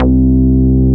P MOOG C3MF.wav